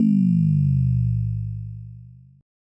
plasma.ogg